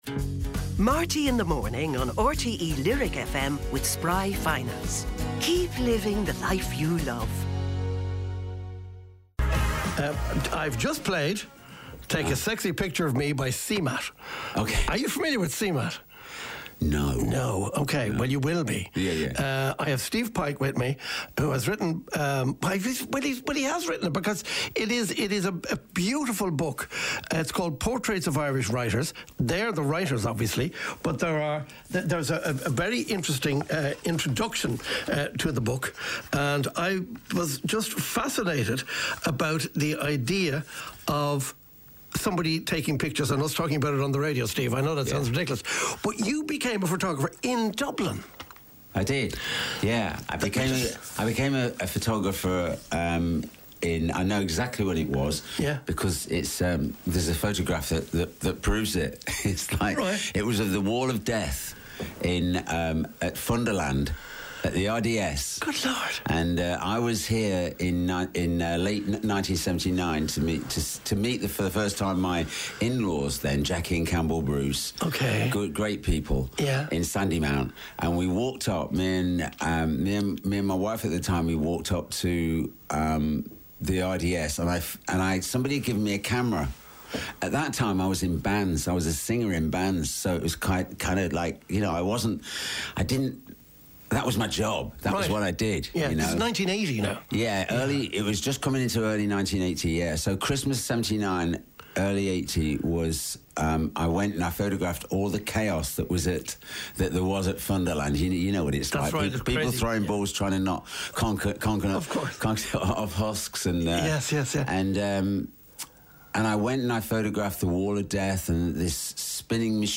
Highlights and special guest interviews and performances from Marty Whelan's breakfast show on RTÉ Lyric FM.